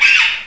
vulture.wav